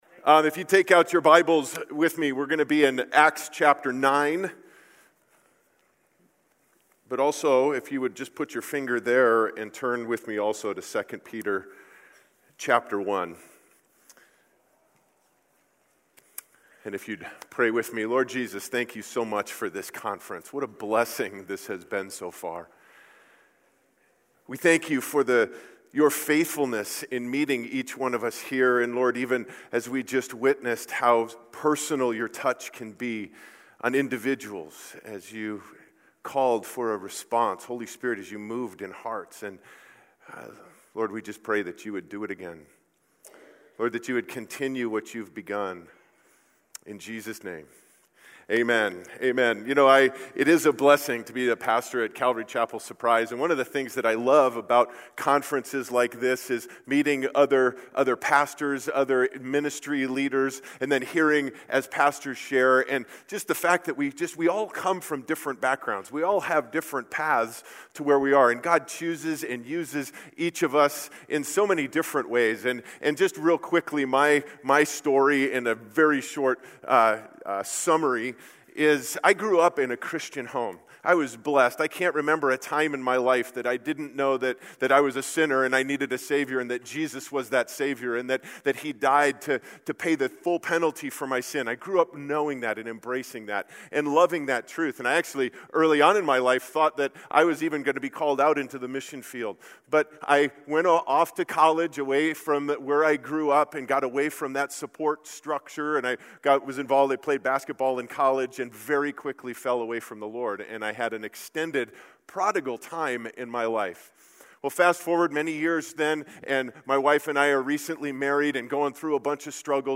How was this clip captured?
Southwest Pastors and Leaders Conference 2018